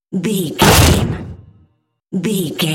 Cinematic stab hit trailer
Sound Effects
Atonal
heavy
intense
dark
aggressive